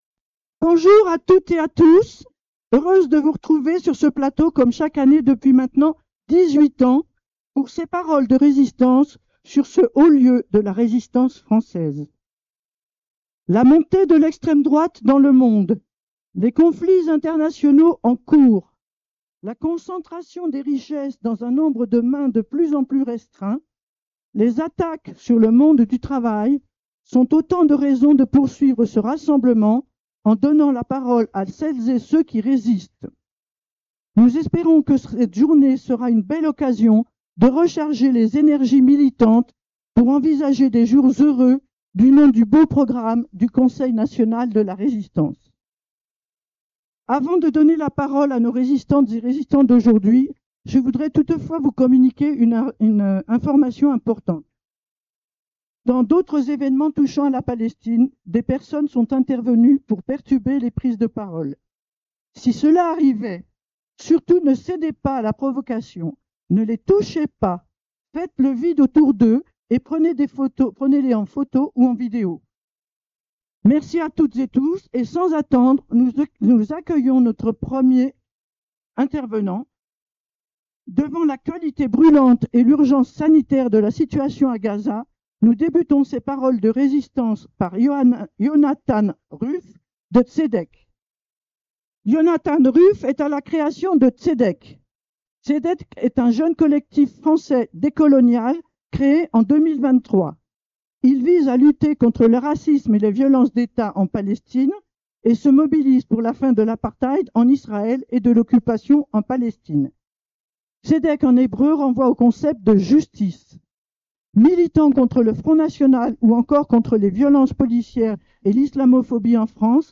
Libradio était sur place pour streamer en live et capturer les conférences et les prises de paroles.
Comme l’année passé nous avons pu collecter le son au sortir de la table de mixage mais sans pouvoir capter les nombreux applaudissements venant du public.
Paroles_Glieres_2025.mp3